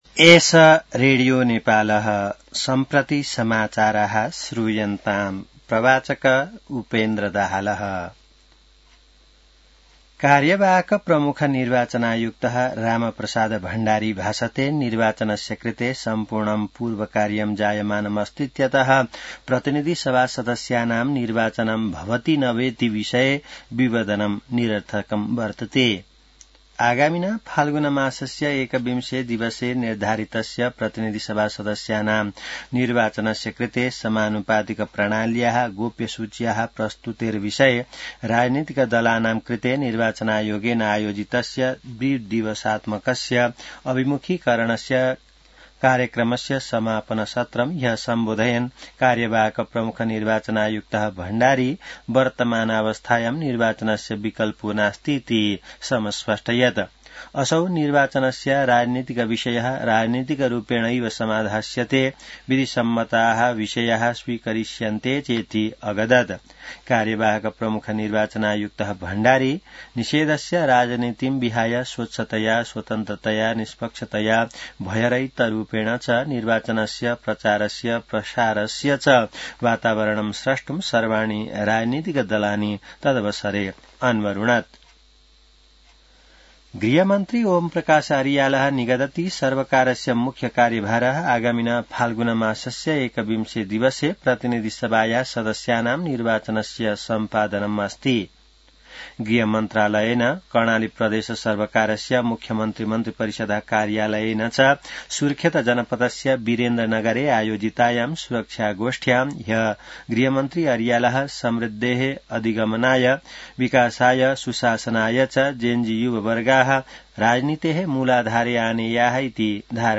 An online outlet of Nepal's national radio broadcaster
संस्कृत समाचार : १० पुष , २०८२